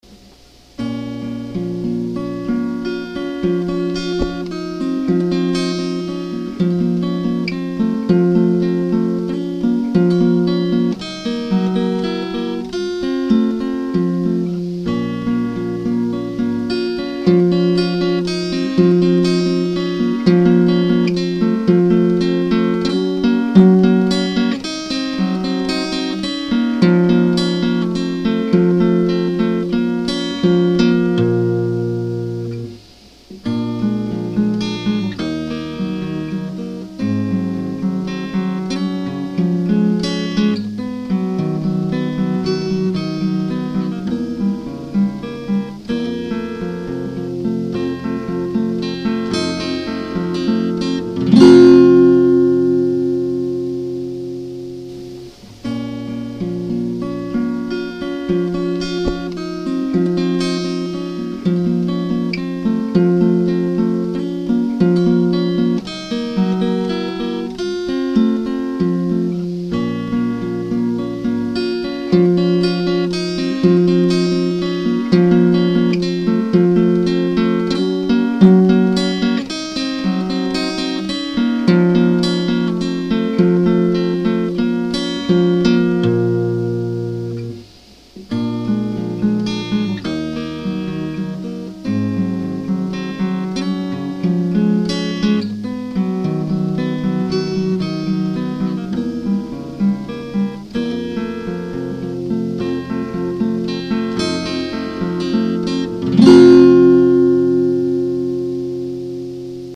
Classical.mp3 Download Single take.